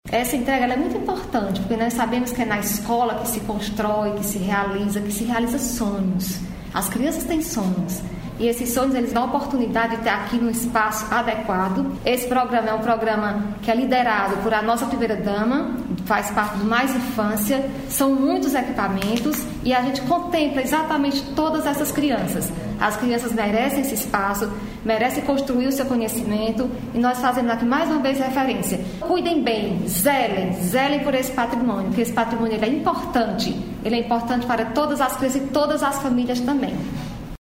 A secretária da Educação, Eliana Estrela, falou da importância do equipamento e reforçou que o equipamento tem que ser cuidado, zelado.